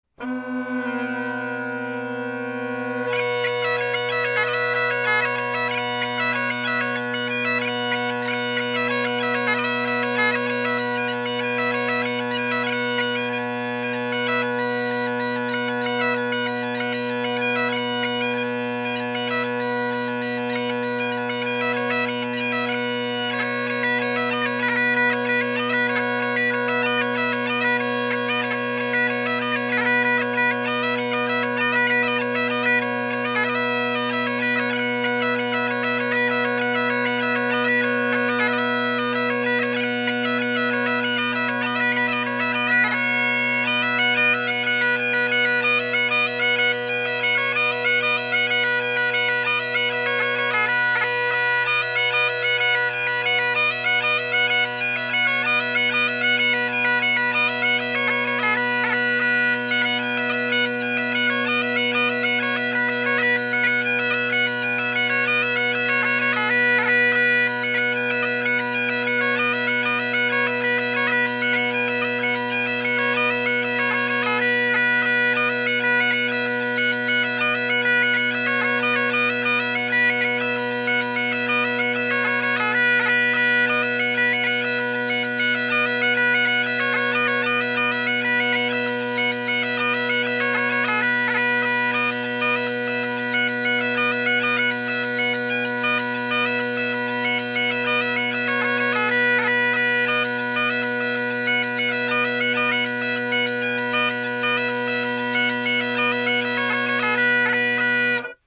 Great Highland Bagpipe Solo
Good volume on the overtones but not harsh, which is nice.
I am sorely out of practice, if you hear any spitting in the last track due to loss of lip seal, DANG IT, the microphone picked it up, lol.
The first one the drones are in tune (at least at the start); the second one the D is in tune (I think).